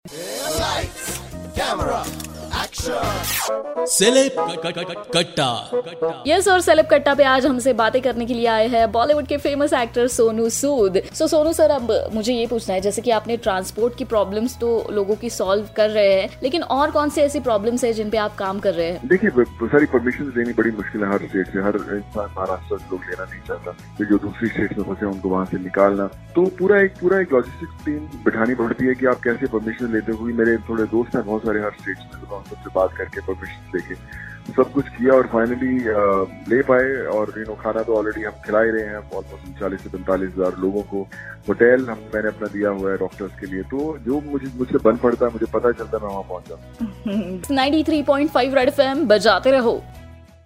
In this interview Sonu Sood also shared some other problems of migrants..